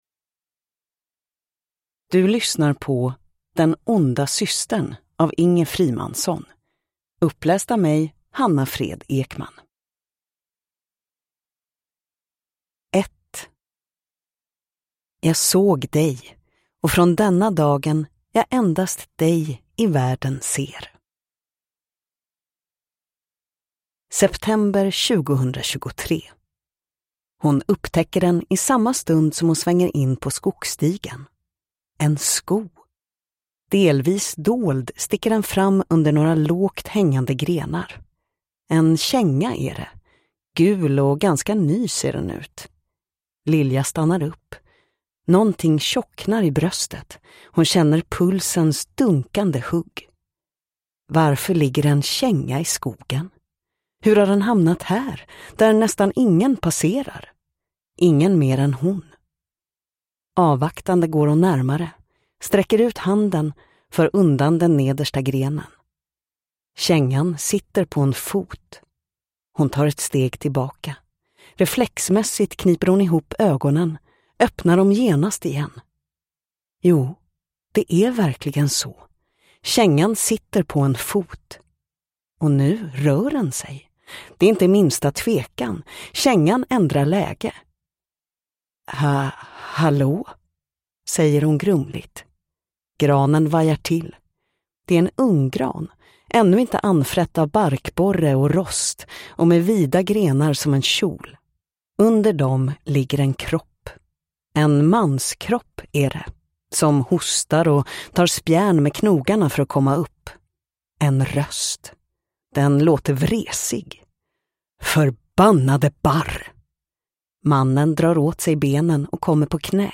Den onda systern – Ljudbok